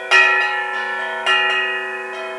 Wow. You really can't get enough cowbell.